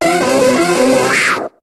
Cri d'Hypnomade dans Pokémon HOME.